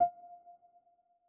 Raise-Your-Wand / Sound / Effects / UI / Modern5.wav